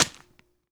Crunch9.wav